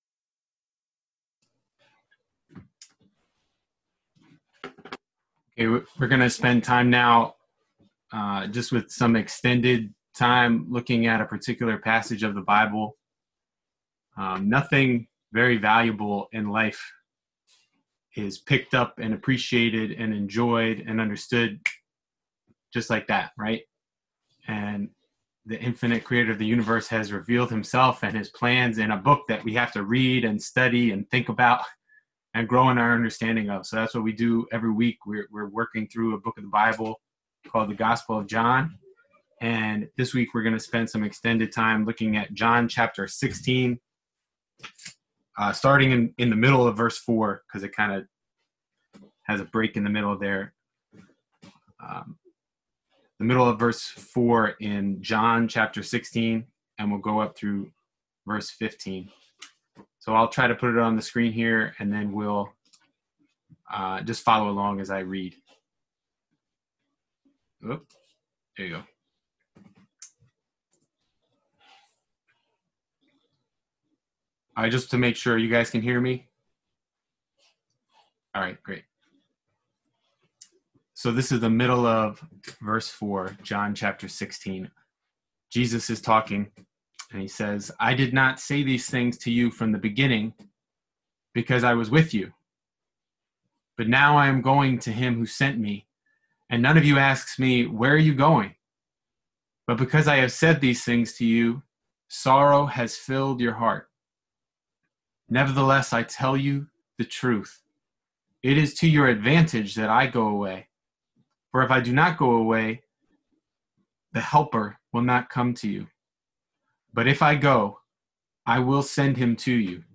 This talk was given over Zoom during the Coronavirus pandemic and NYS shutdown.
Service Type: Sunday Morning